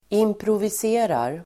Ladda ner uttalet
Uttal: [improvis'e:rar]